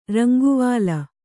♪ ranguvāla